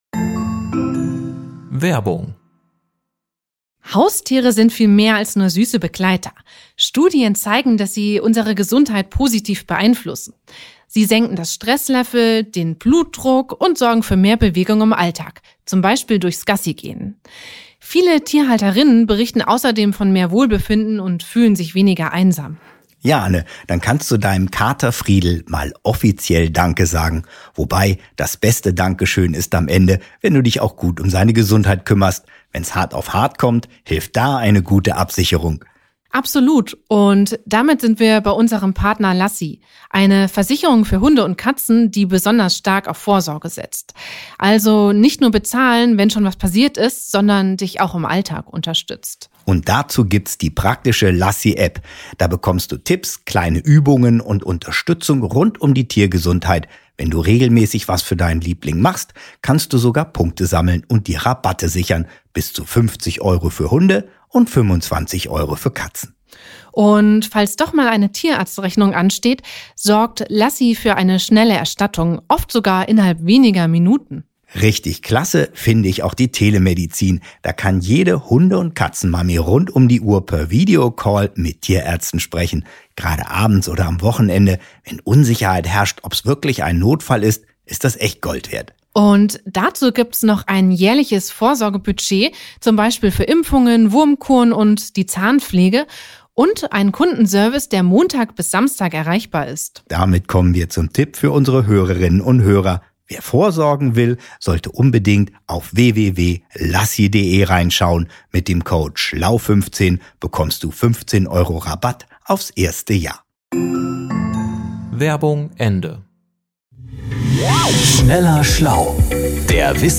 im Gespräch mit Tech-Journalistin